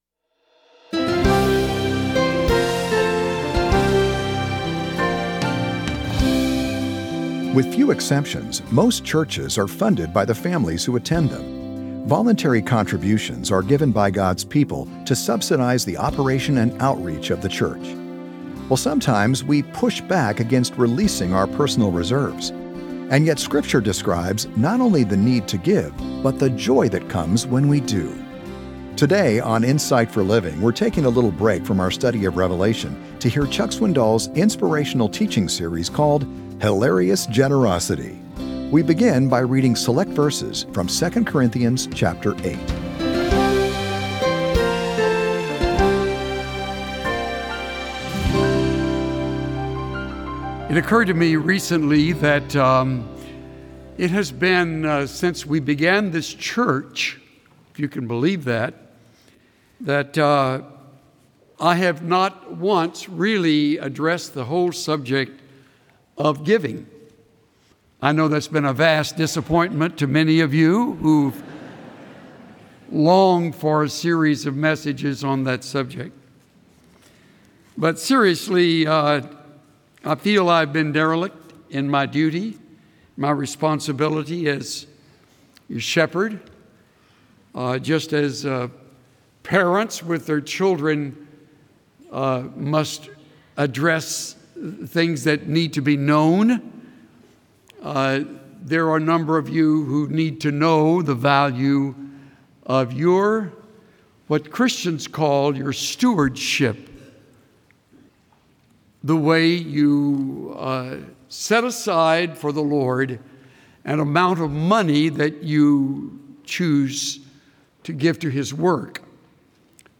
Insight for Living Daily Broadcast